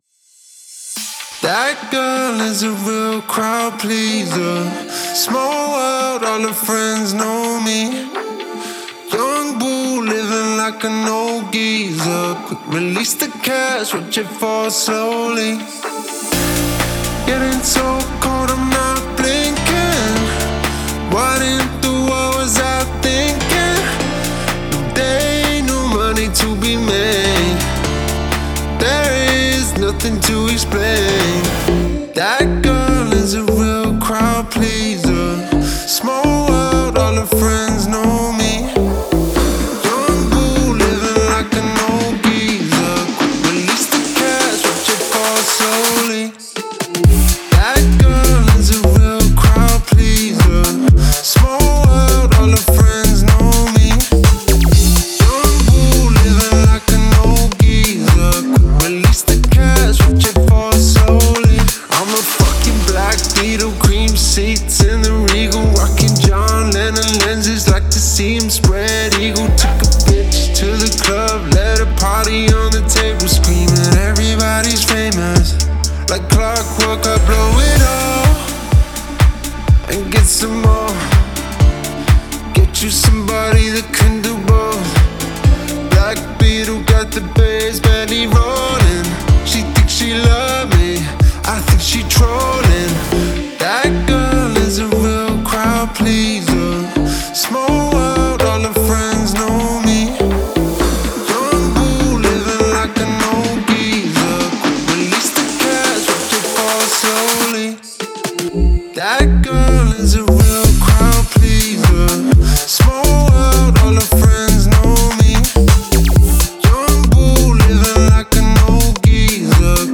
это неординарная композиция в жанре альтернативного хип-хопа